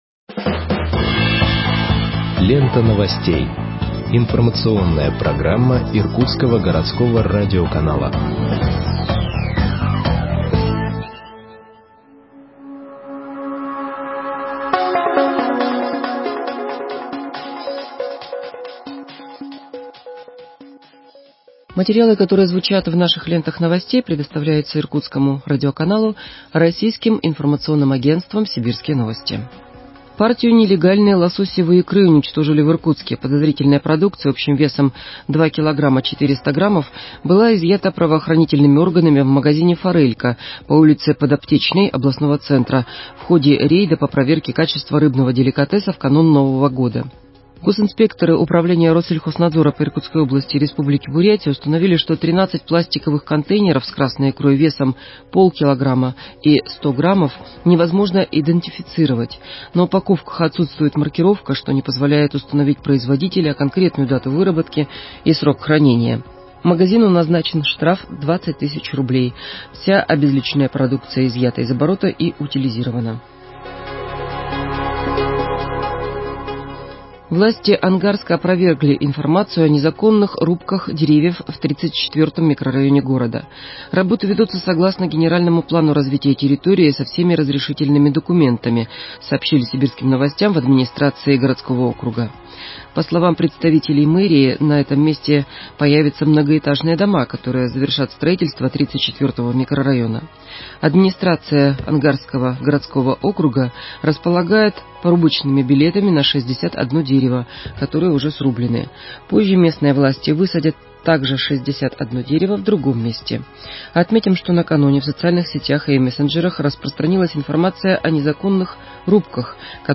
Выпуск новостей в подкастах газеты Иркутск от 11.02.2021 № 1